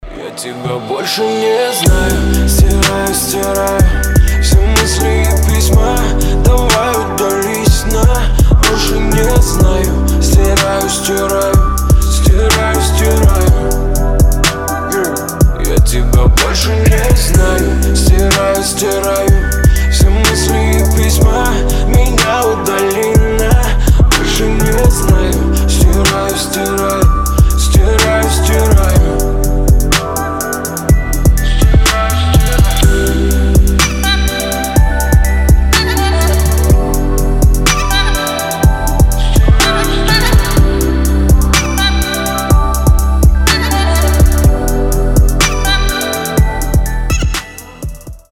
• Качество: 320, Stereo
лирика
Хип-хоп
мелодичные
красивая мелодия